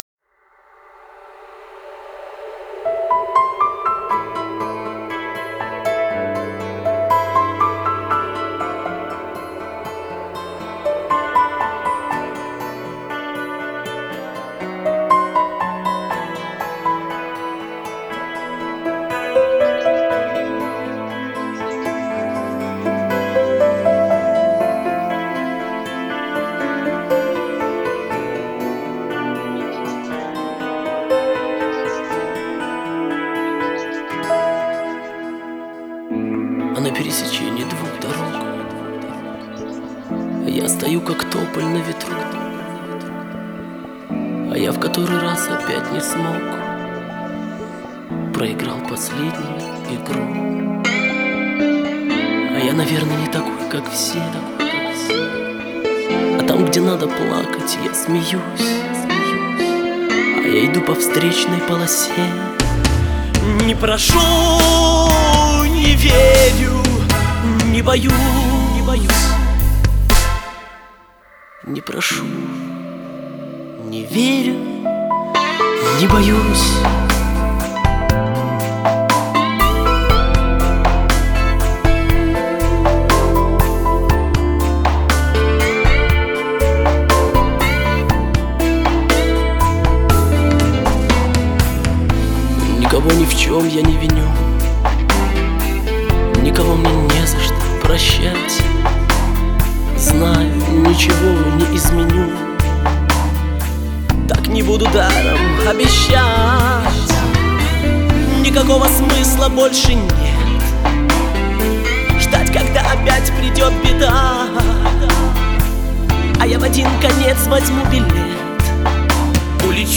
سبک آلبوم: پاپ